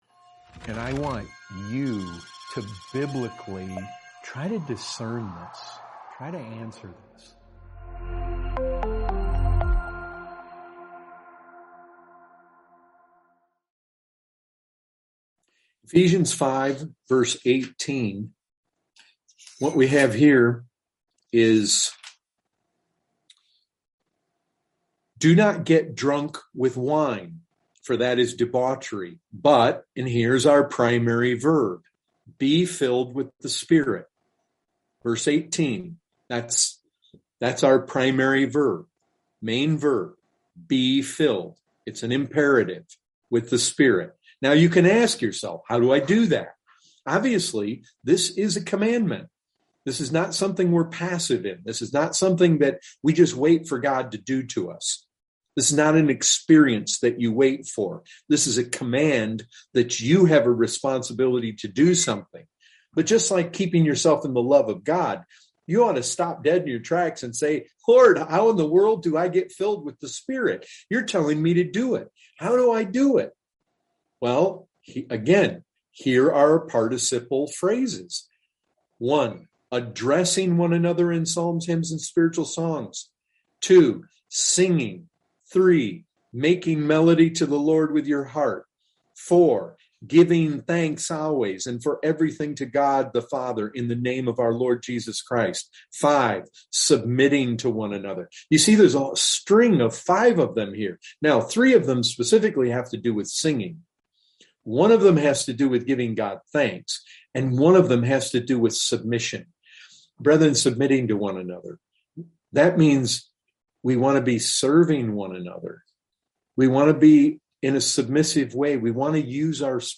2021 Category: Questions & Answers Are you a thankful person or do you constantly grumble at the providences of God in your life?